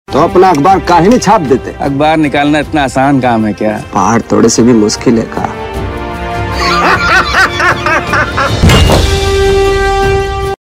Bollywood Dialogue Tones